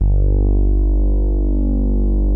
MOOG #9  G2.wav